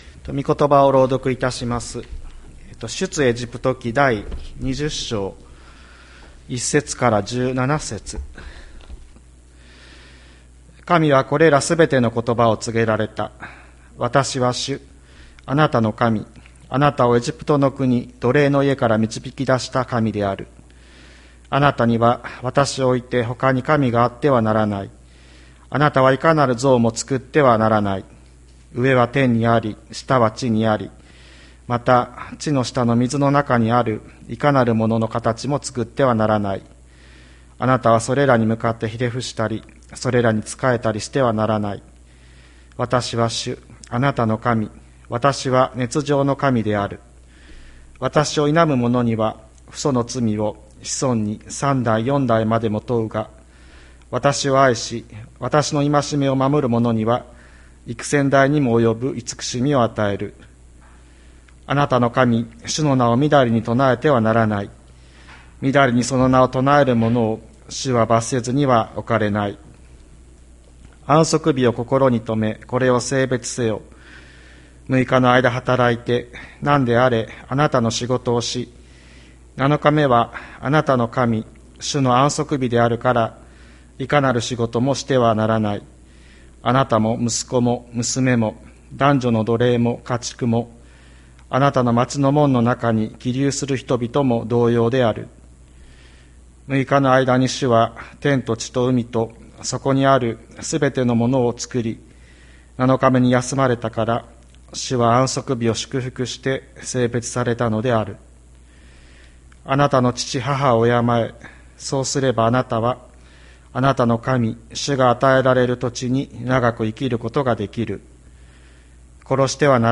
2022年05月22日朝の礼拝「真実を口にして生きる」吹田市千里山のキリスト教会
千里山教会 2022年05月22日の礼拝メッセージ。